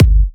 VEC3 Bassdrums Trance 35.wav